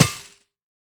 Monster_Spawner_break3_JE1_BE1.wav